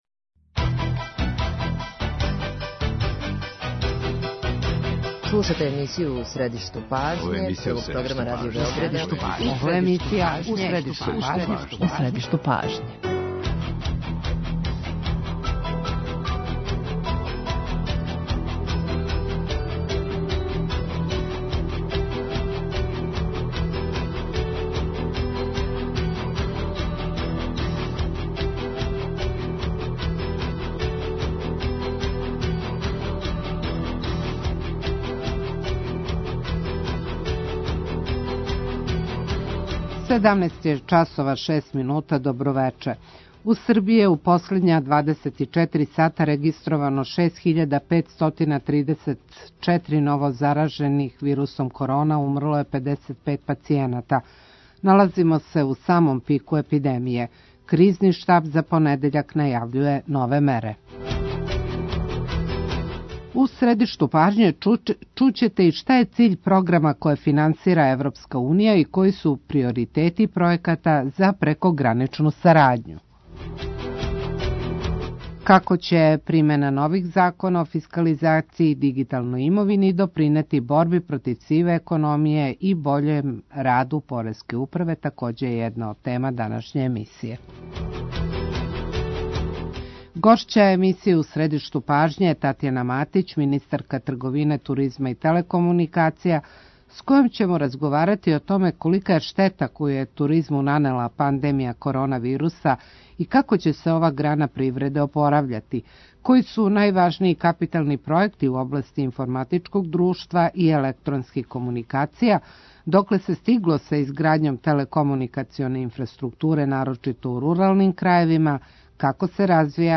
Свакога радног дана емисија "У средишту пажње" доноси интервју са нашим најбољим аналитичарима и коментаторима, политичарима и експертима, друштвеним иноваторима и другим познатим личностима, или личностима које ће убрзо постати познате.
Колика је штета коју је туризму нанела пандемија корона вируса и како ће се ова грана привреде опорављати, који су најважнији капитални пројекти у области информатичког друштва и електронских комуникација, докле се стигло са изградњом телекомуникационе инфраструктуре нарочито у руралним крајевима, како се развија електронска трговина? Гошћа емисије је Татјана Матић, министарка трговине, туризма и телекомуникација.